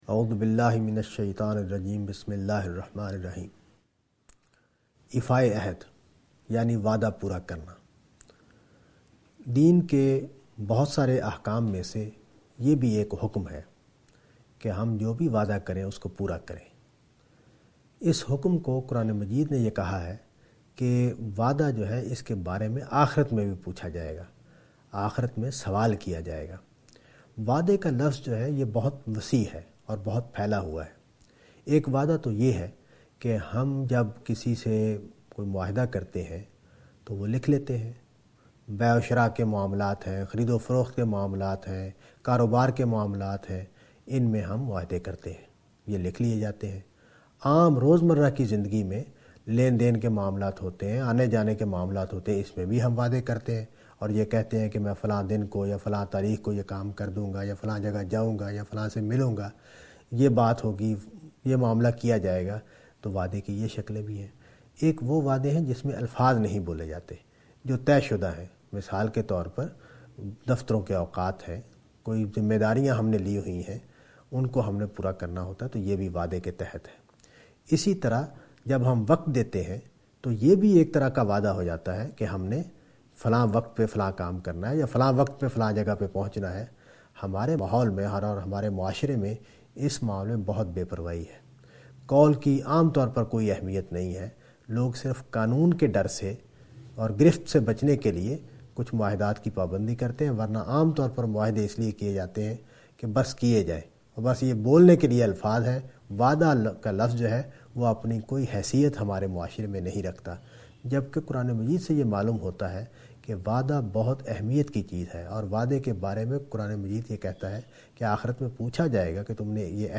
A short talk